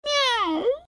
Converted sound effects
AV_cat_question.ogg